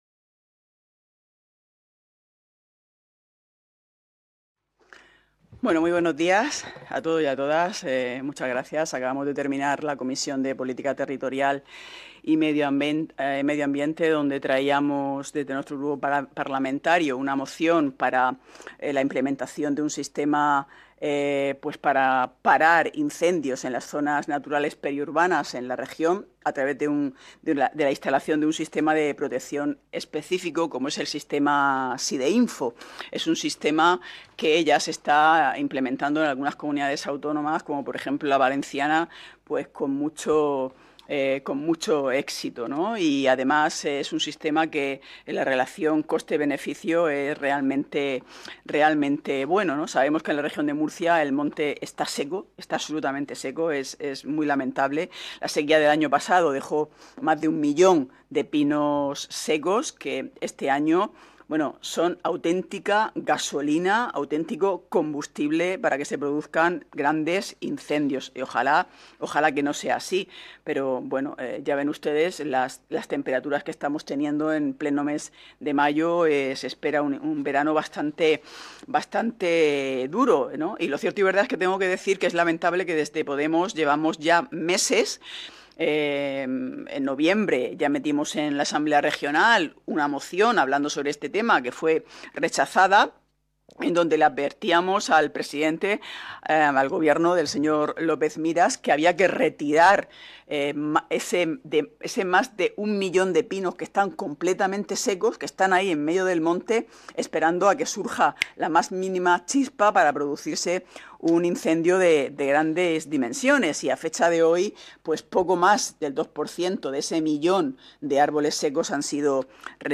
Ruedas de prensa tras la Comisión de Política Territorial, Medio Ambiente, Agricultura y Agua
• Grupo Parlamentario Vox
• Grupo Parlamentario Mixto